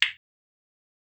claquement-4.wav